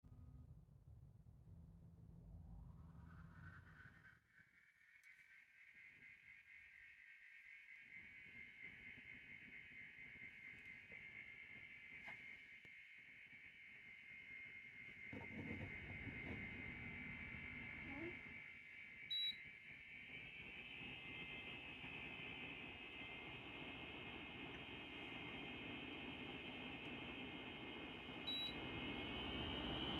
La ventilation de l'unité intérieure s'est mise à faire un bruit aigu, plus ou moins fort selon la vitesse de ventilation.
Commence en vitesse lente, puis moyenne, puis rapide...
Bruit ventilation unité intérieure Daikin
ventilation-daikin-bruit.mp3